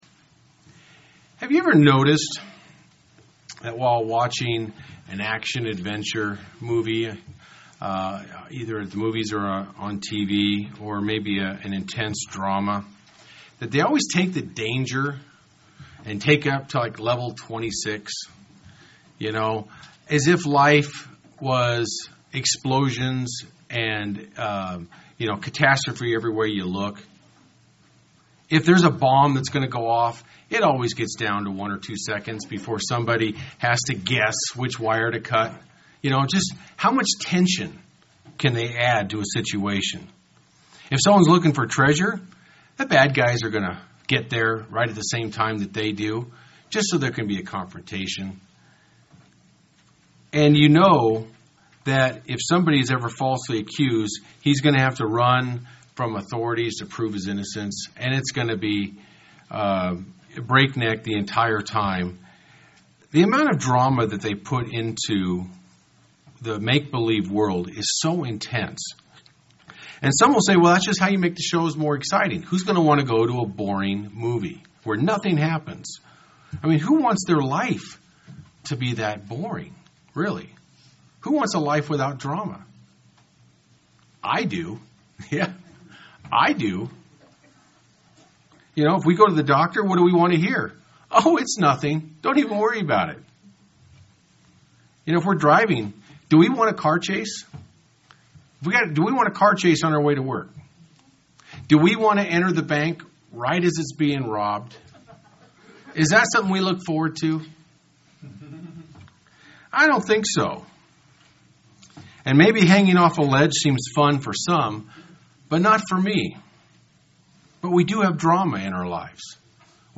UCG Sermon Notes Have you ever noticed that when watching an action adventure movie or intense drama that they always play up the danger or imminent peril?